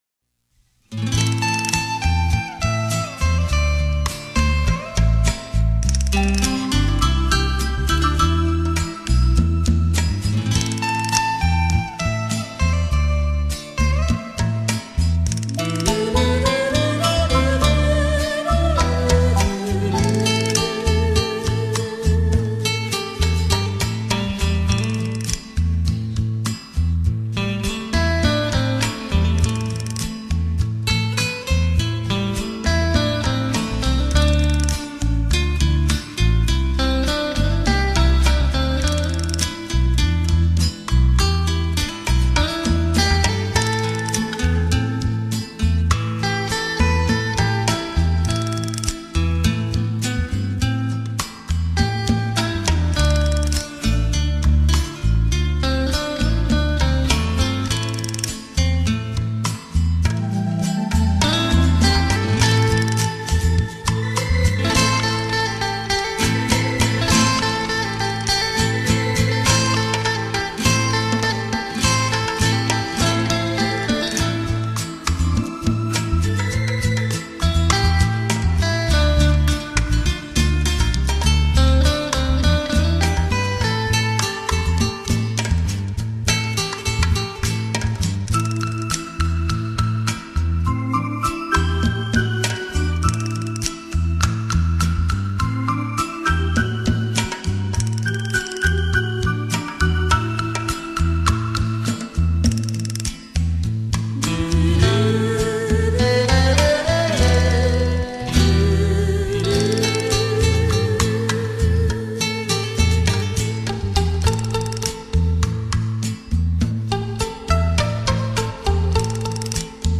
吉他的乐声，从不曾消失在你我的生命中